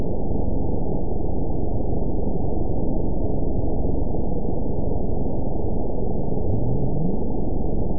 event 920489 date 03/28/24 time 00:45:31 GMT (1 year, 1 month ago) score 9.53 location TSS-AB01 detected by nrw target species NRW annotations +NRW Spectrogram: Frequency (kHz) vs. Time (s) audio not available .wav